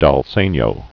(däl sānyō)